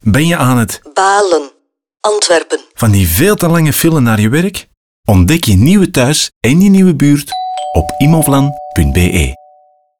Radiospot_Immovlan_Balen